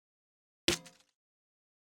Системные звуки Apple iMac и MacBook Pro и Air в mp3 формате
2. Звук удаления (файла, папки и т.д) на маке
imac-udalenie-faila.mp3